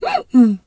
zebra_breath_wheeze_03.wav